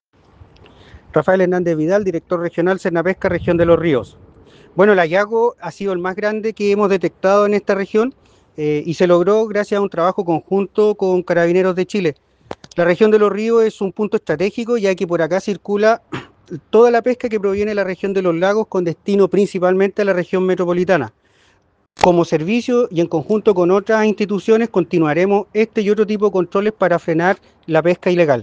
RafaelHernandez_DirectorRegional.ogg